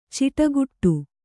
♪ ciṭaguṭṭu